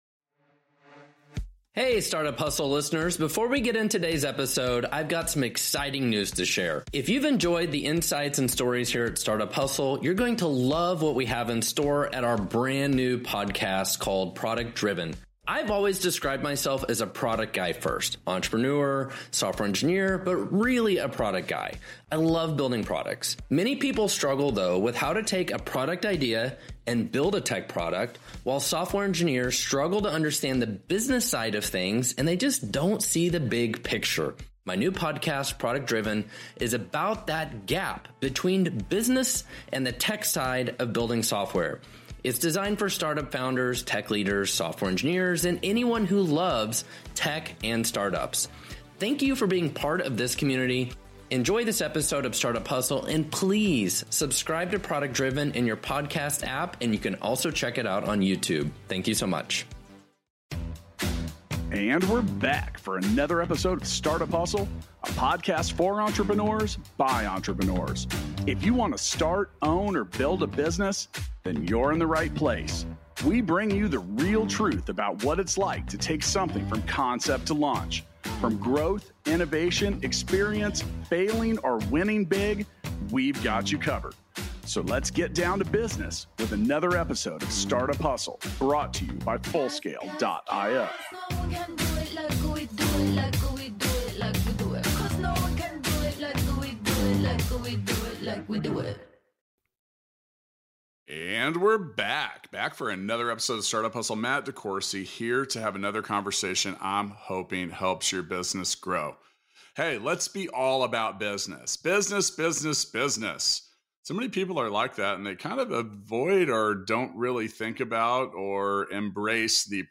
for an interesting conversation about the personal approach to business. They share valuable lessons learned from approaching selling with a personal touch and empathy.